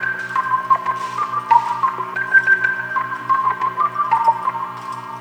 Back Alley Cat (Organ 03).wav